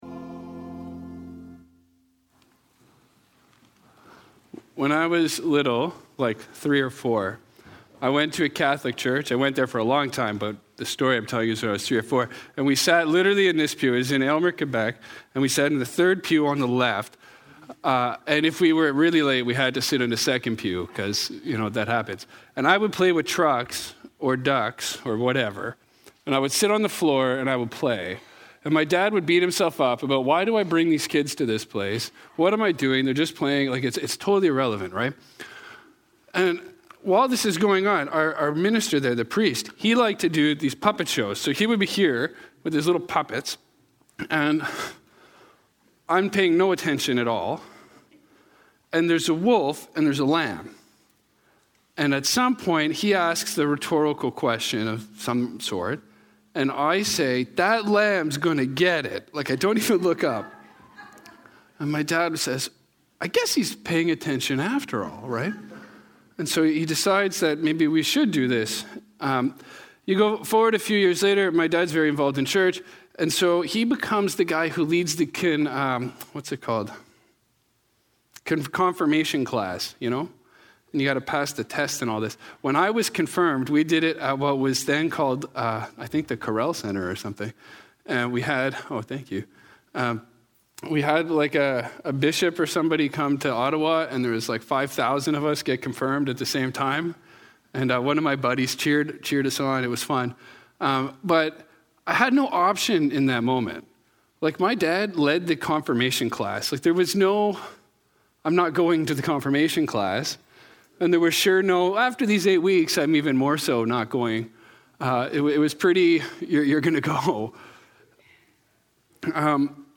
Children and the Sacraments – Westminster Presbyterian Church